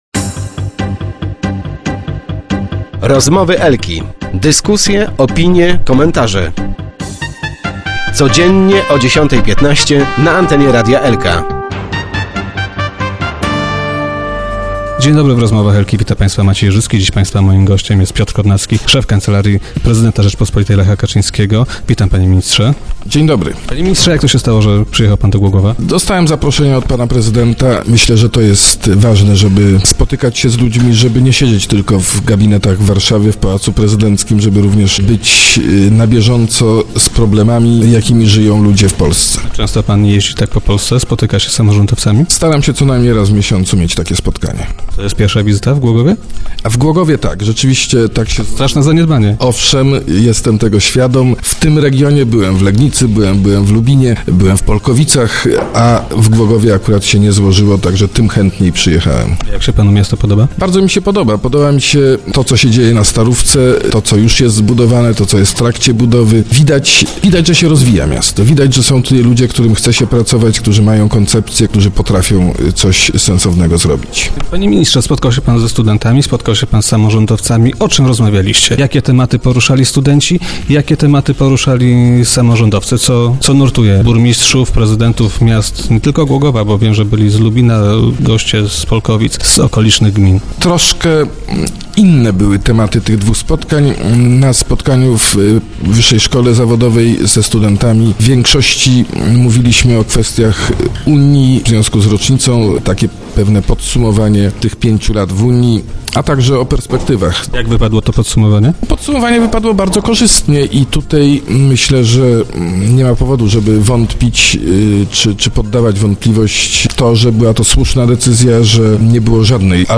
- Zdaję sobie sprawę, że jest to pewne zaniedbanie - powiedział Piotr Kownacki w dzisiejszych Rozmowach Elki.